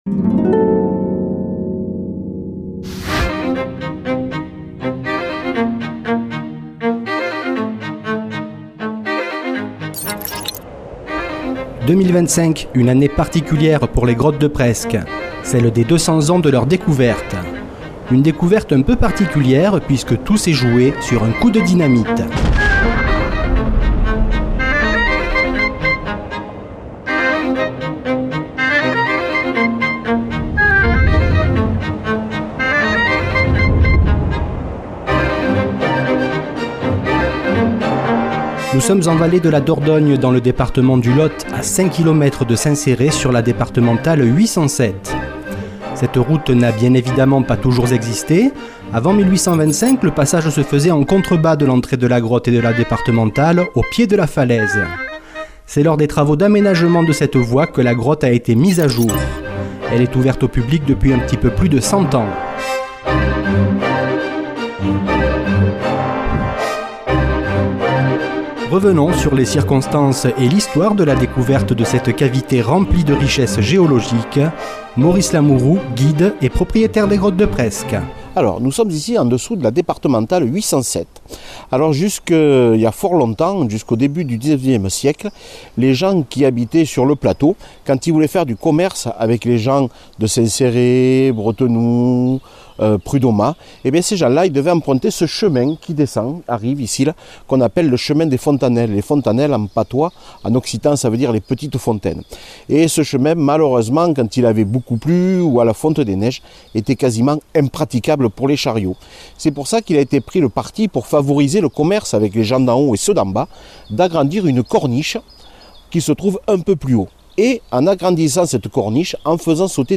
Reportage Grottes de Presque